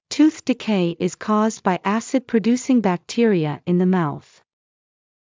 ﾄｩｰｽ ﾃﾞｨｹｲ ｲｽﾞ ｺｳｽﾞﾄﾞ ﾊﾞｲ ｱｼｯﾄﾞ ﾌﾟﾛﾃﾞｭｰｼﾝｸﾞ ﾊﾞｸﾃﾘｱ ｲﾝ ｻﾞ ﾏｳｽ